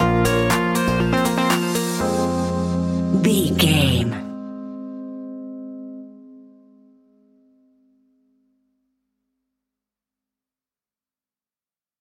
Funky Synth Electro Stinger.
Aeolian/Minor
groovy
uplifting
driving
energetic
drum machine
synthesiser
funky house
upbeat
synth bass